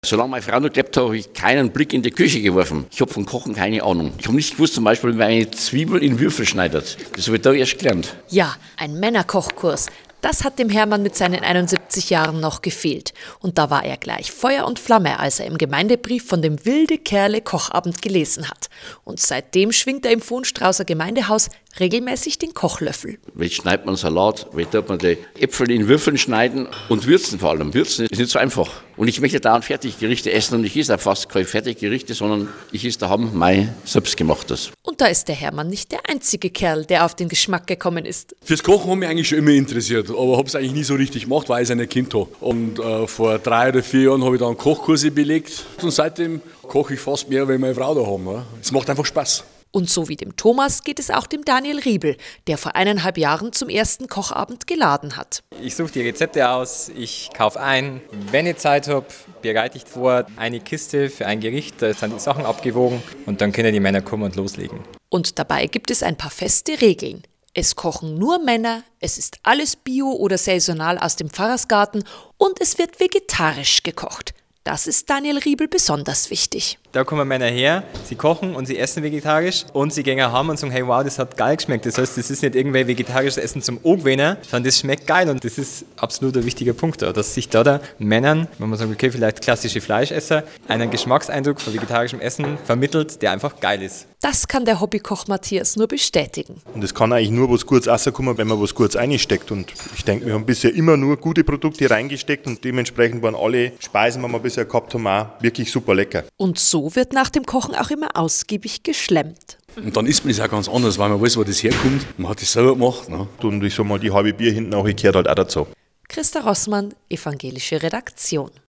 Radiobeitrag (1,81 MB), 1:59 min, MP3